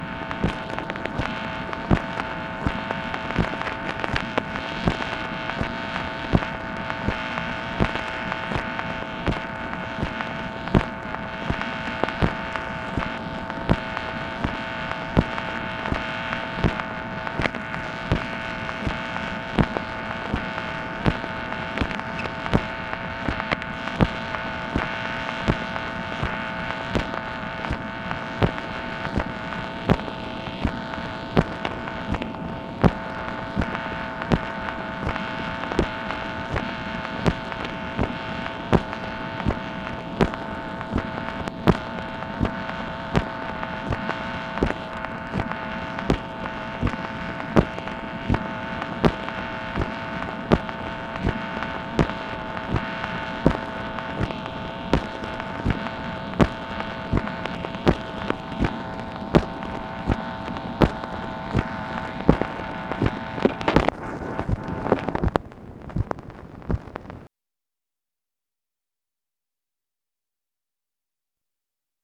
MACHINE NOISE, December 17, 1966
Secret White House Tapes | Lyndon B. Johnson Presidency